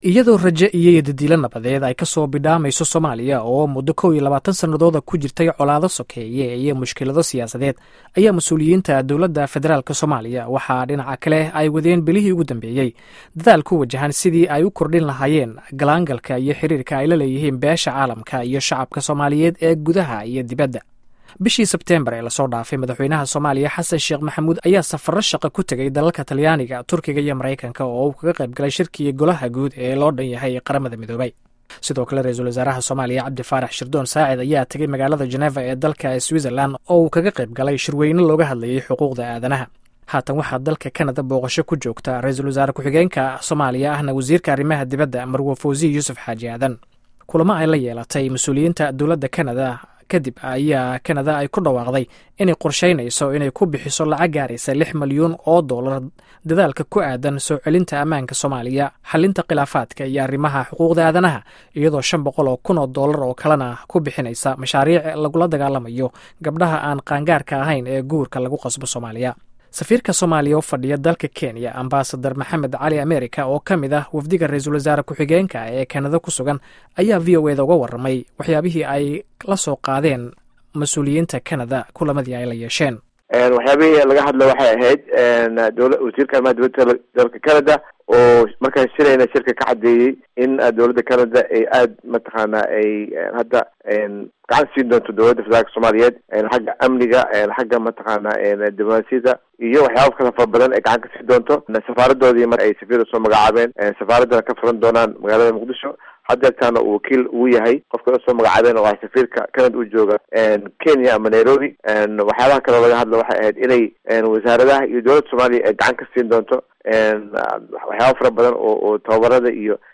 Dhageyso warbixinta Xafladda Torronto